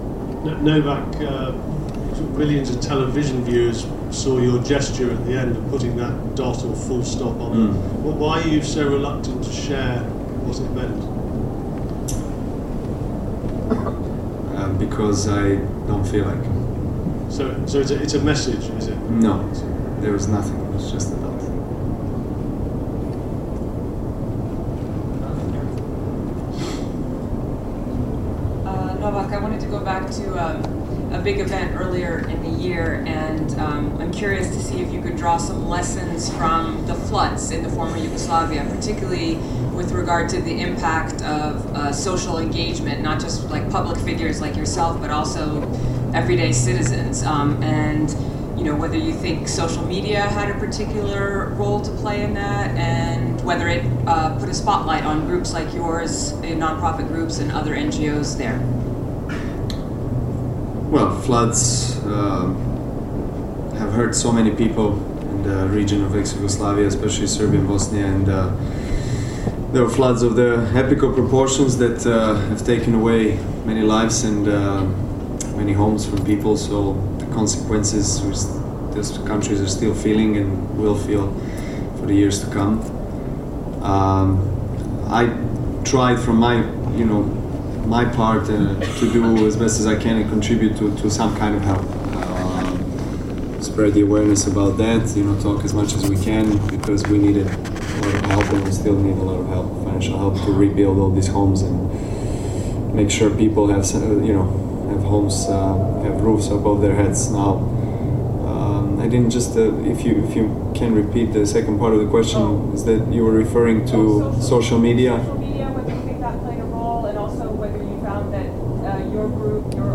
Conferenza Stampa Djokovic: